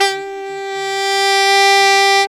SAX JN SWE05.wav